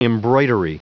Prononciation du mot embroidery en anglais (fichier audio)
embroidery.wav